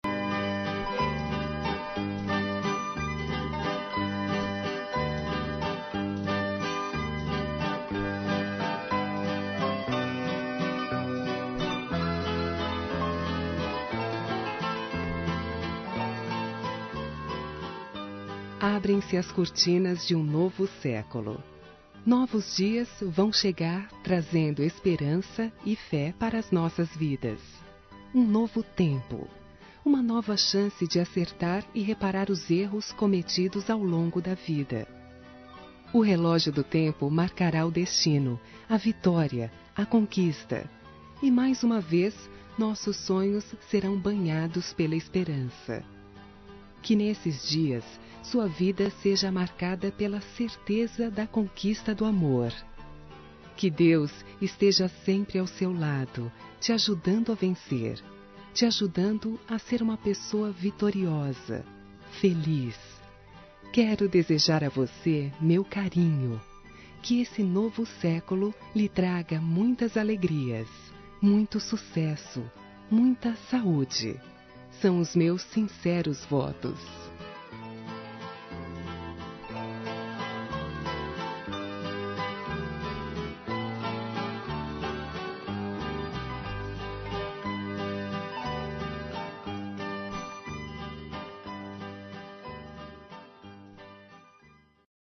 Ano Novo – Pessoa Especial – Voz Feminina – Cód: 6405